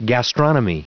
Prononciation du mot gastronomy en anglais (fichier audio)
gastronomy.wav